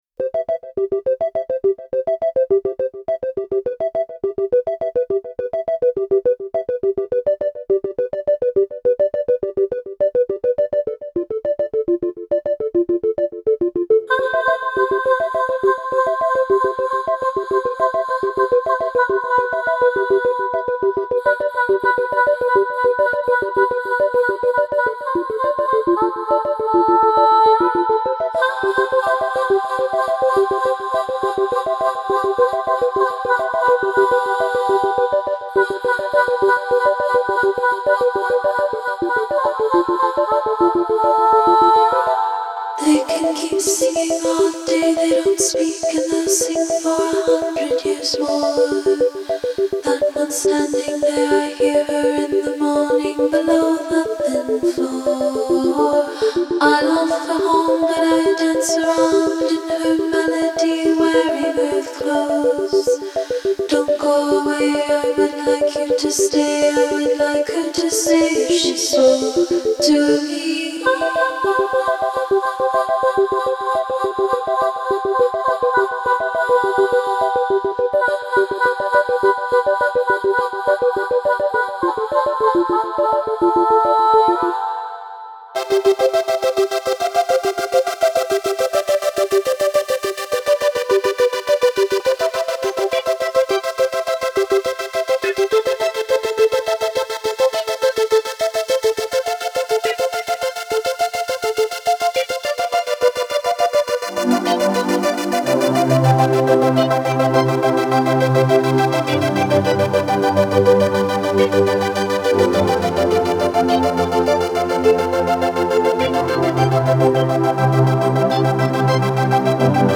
Genre: Electronic